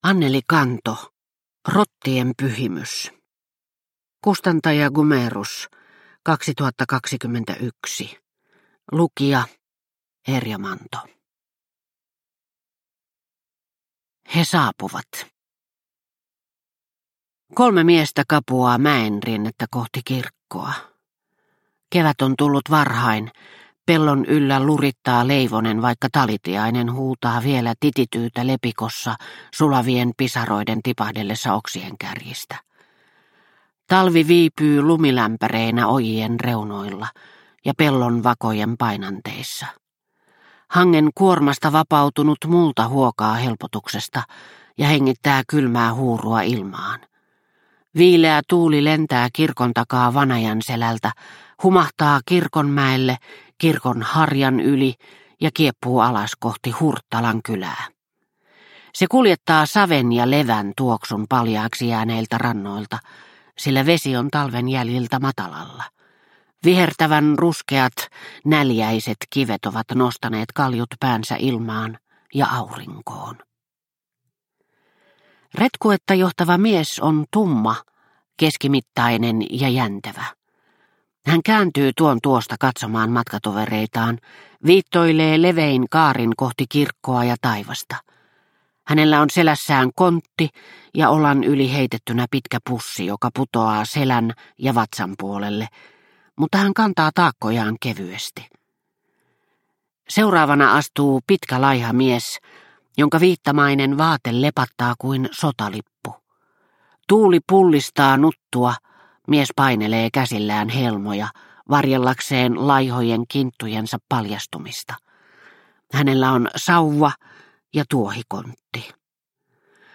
Rottien pyhimys – Ljudbok – Laddas ner